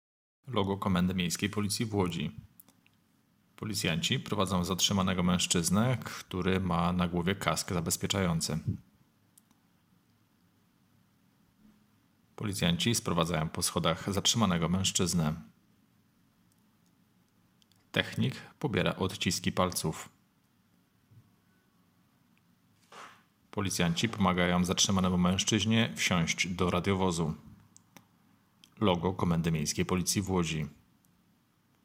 Nagranie audio deskrypcja_filmu.m4a